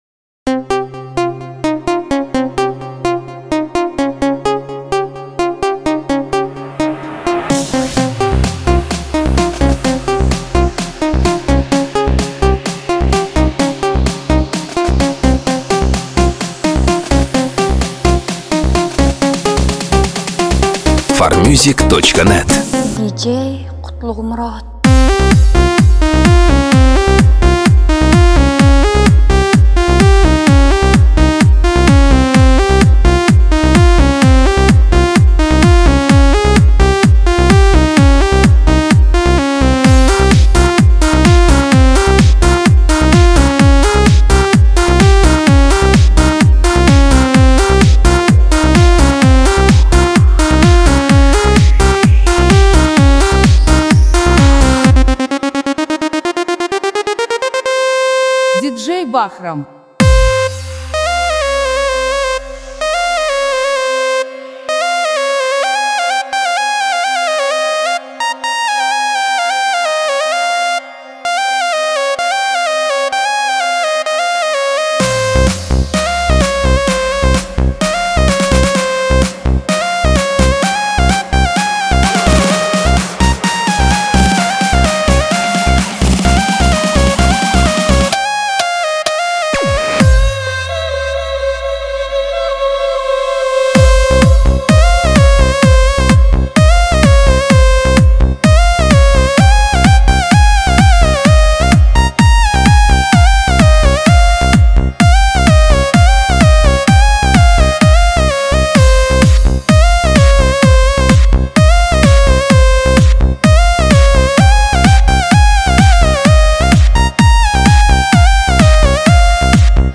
Клубные песни